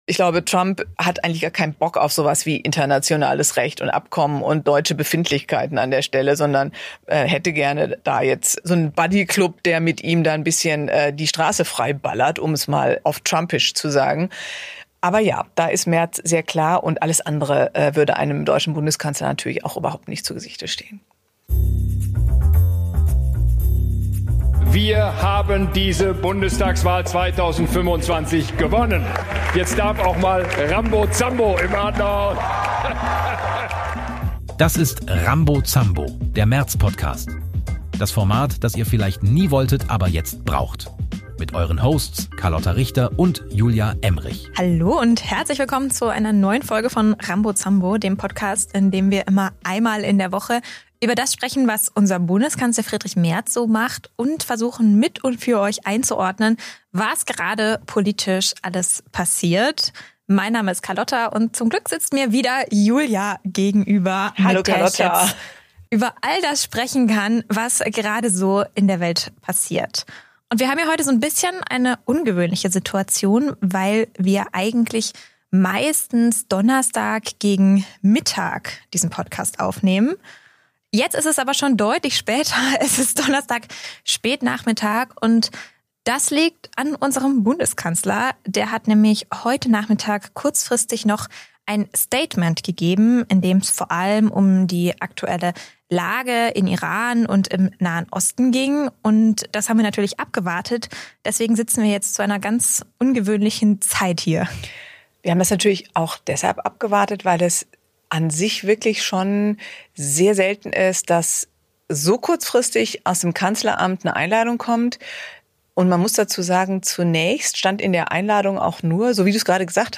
Zwei Frauen. Ein Kanzler.